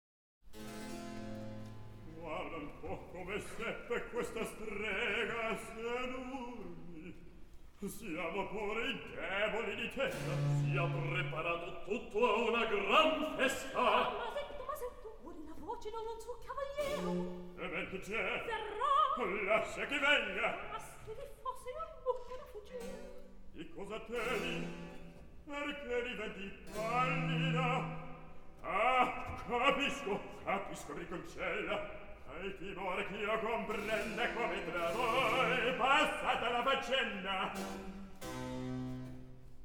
Recitativo.